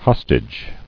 [hos·tage]